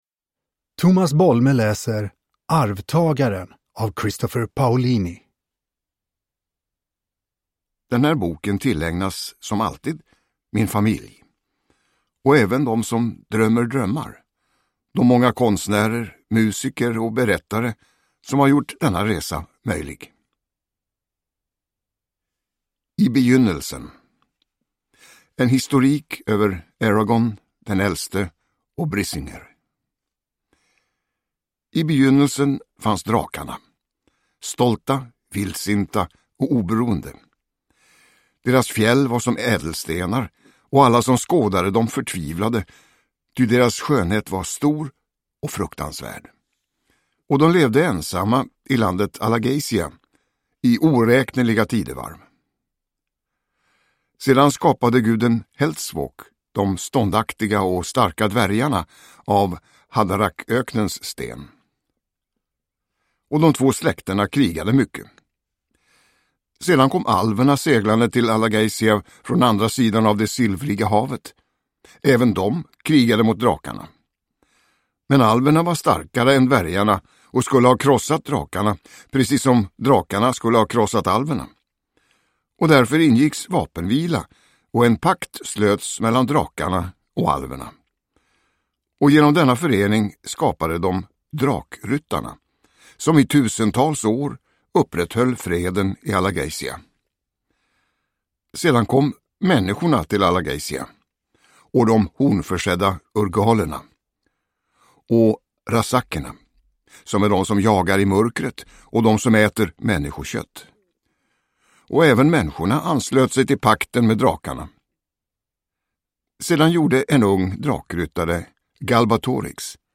Arvtagaren – Ljudbok
Uppläsare: Tomas Bolme